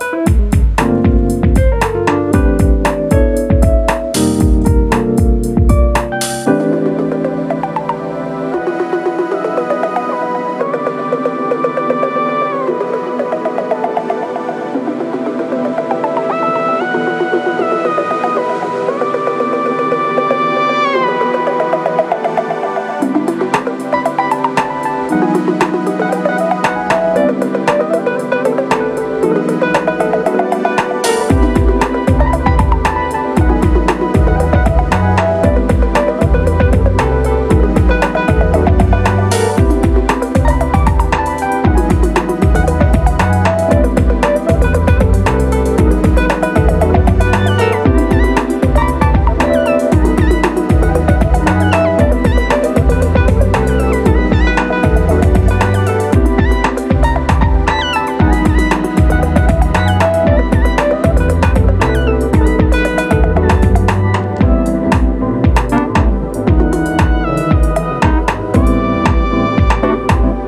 acidic cut
percussions